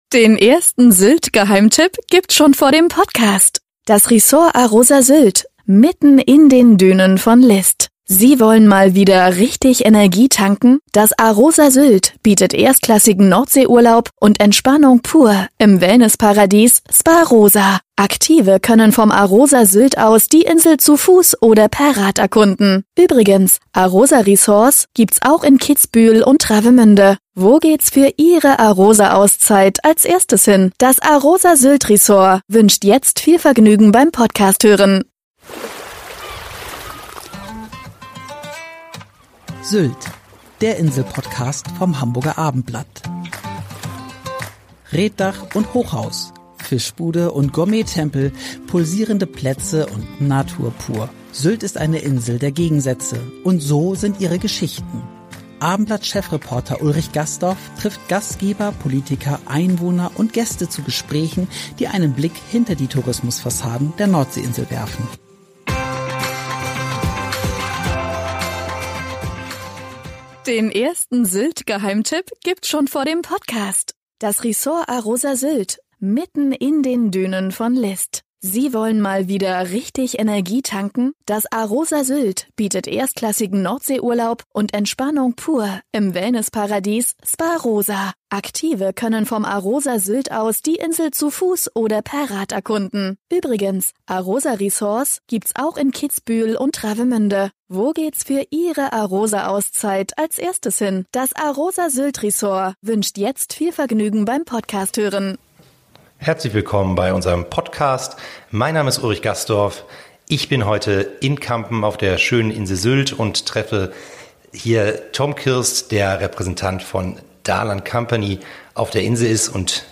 Gespräche, die einen Blick hinter die Tourismus-Fassaden der Nordsee-Insel werfen